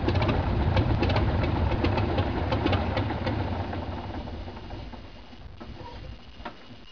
Descarga de Sonidos mp3 Gratis: avion 2.
aircraft011.mp3